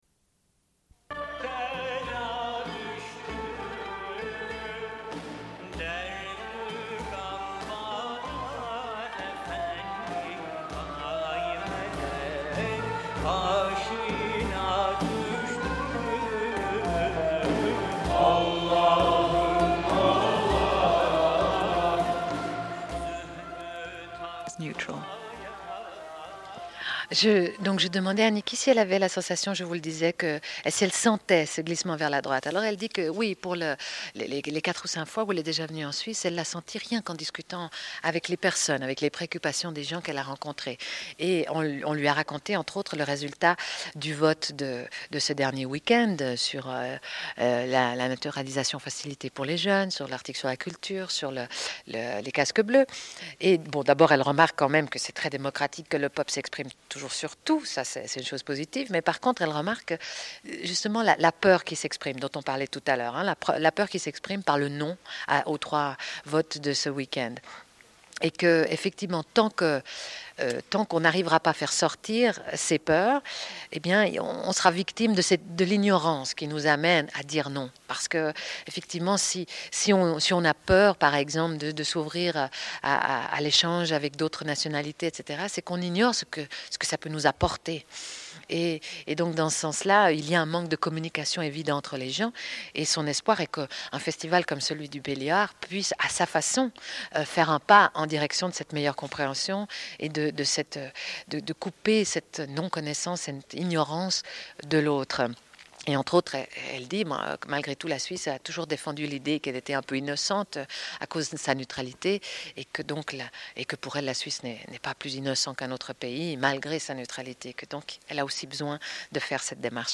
Une cassette audio, face A31:04